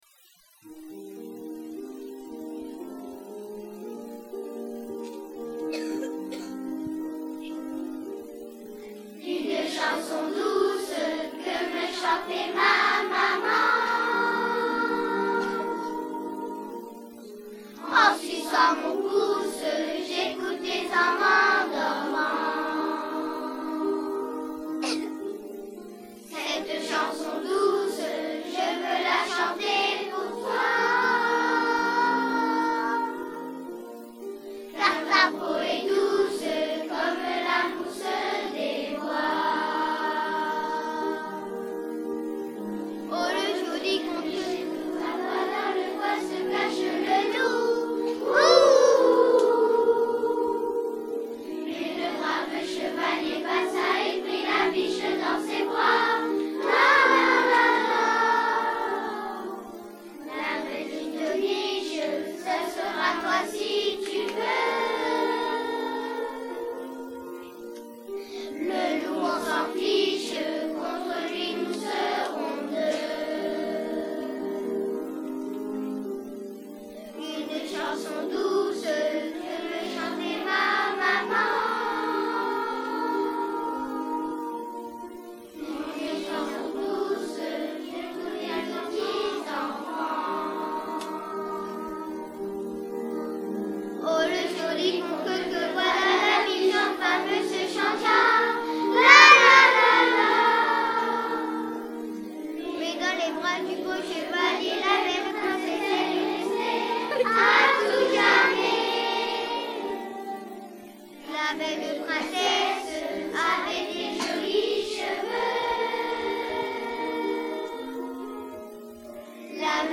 300 élèves de Dunkerque
Vu le nombre des enfants, le résultat n'a pas la qualité d'un véritable travail de chorale, mais chacun essaie d'y mettre de la bonne volonté et des notes plutôt justes !
C'est la répétition seulement !